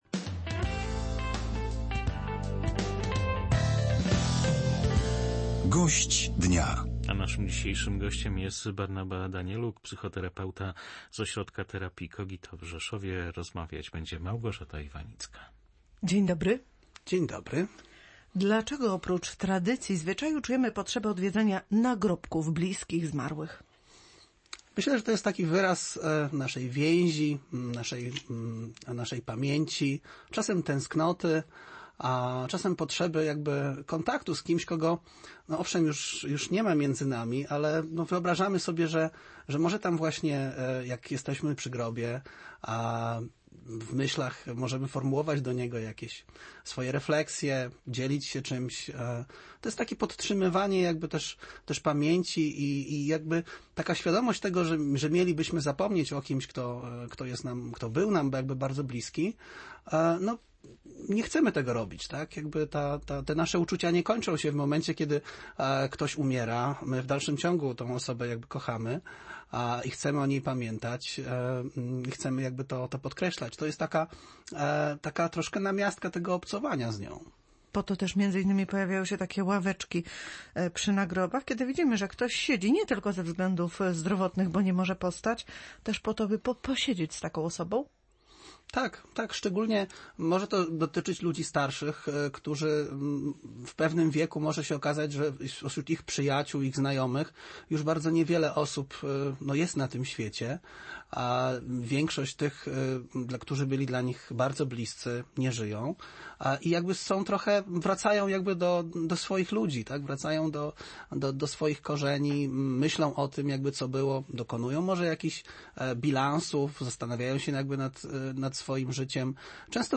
Audycje